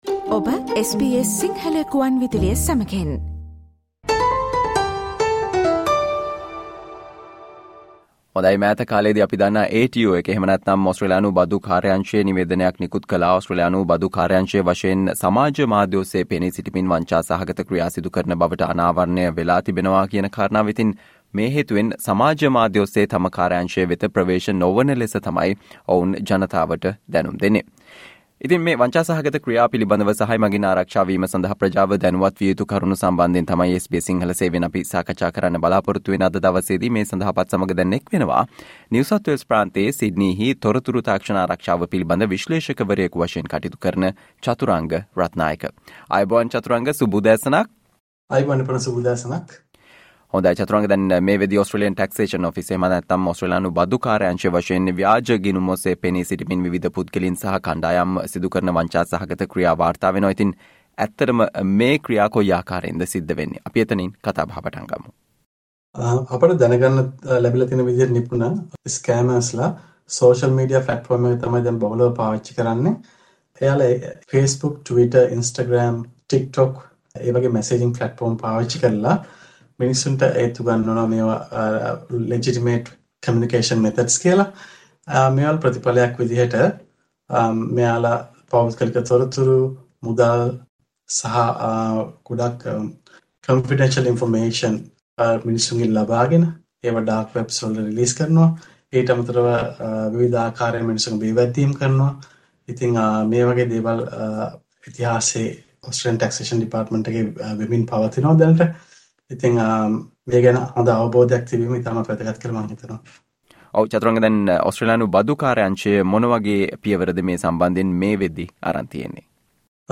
ඕස්ට්රේලියානු බදු කාර්යාංශය වශයෙන් සමාජ මාධ්‍ය ඔස්සේ පෙනී සිටිමින් සිදු කරන වංචා සහ එමගින් ආරක්ෂා වීමට ප්‍රජාව දැනුවත් විය යුතු කරුණු පිළිබඳව SBS සිංහල සේවය සිදු කල සාකච්චාවට සවන් දෙන්න